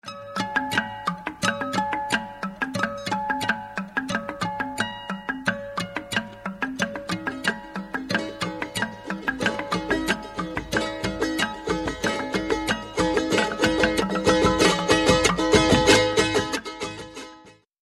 Dieses Perkussionsinstrument ist erstaunlich melodiös!